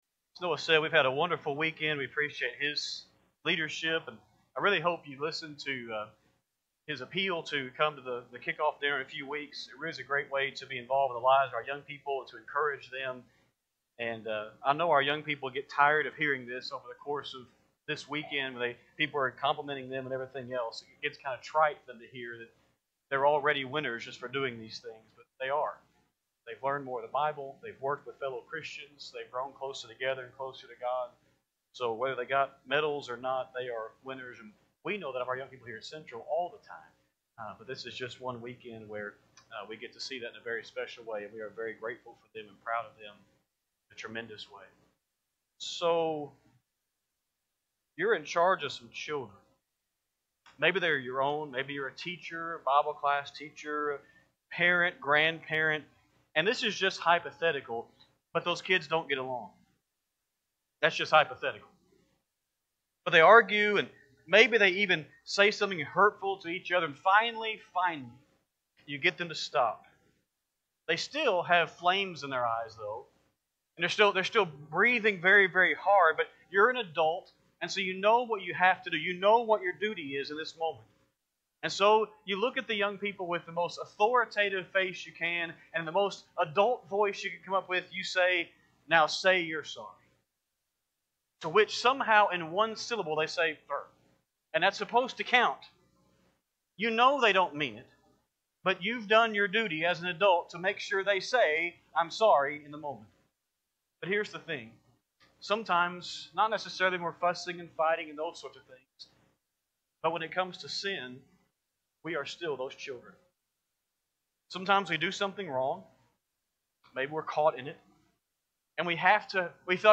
4-5-26-Sunday-PM-Sermon.mp3